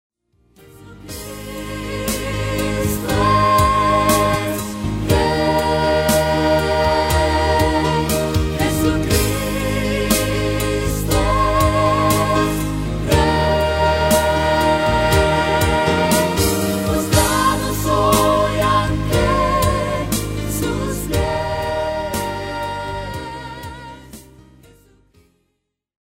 álbum clásico de adoración